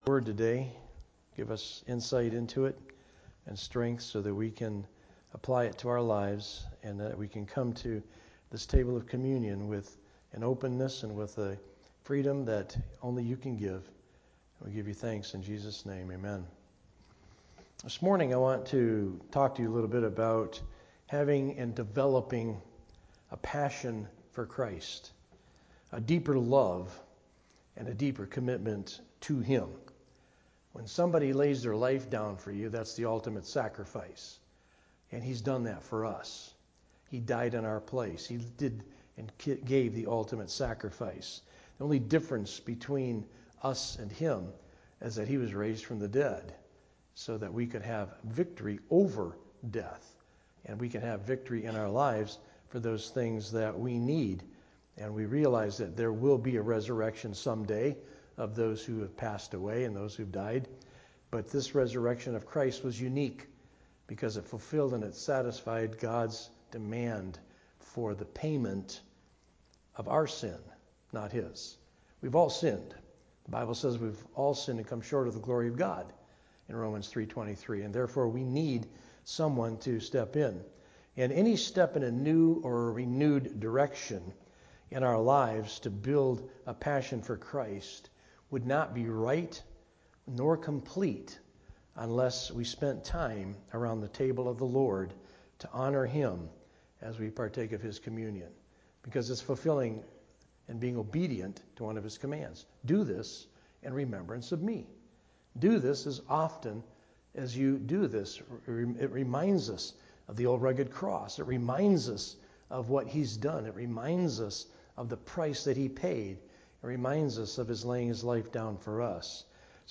A message from the series "Sunday Morning - 11:00."